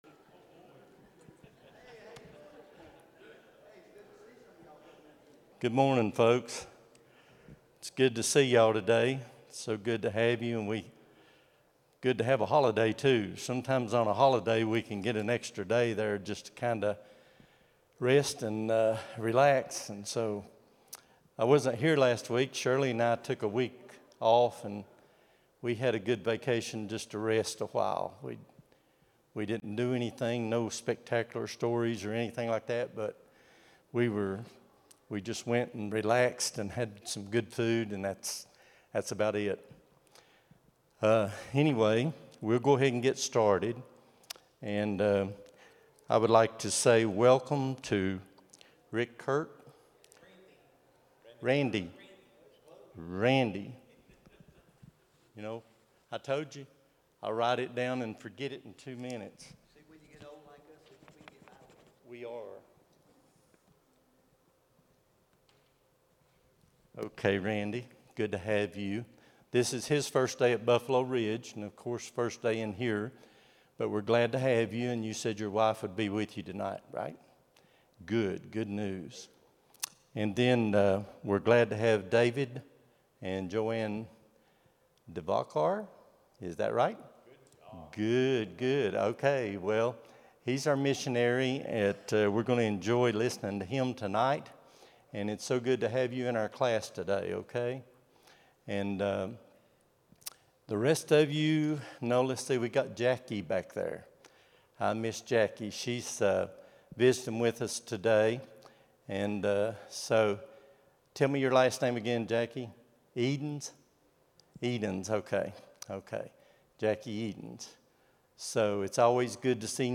05-25-25 Sunday School | Buffalo Ridge Baptist Church